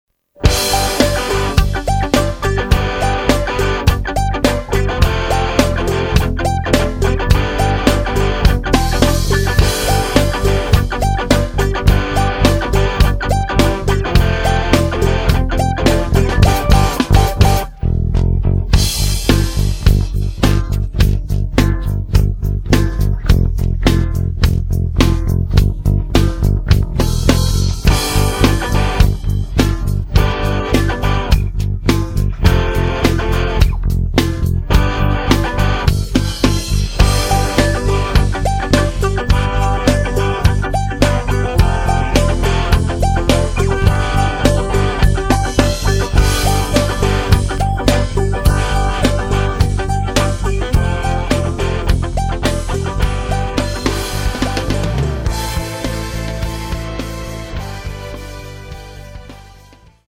음정 원키 3:16
장르 가요 구분 Voice Cut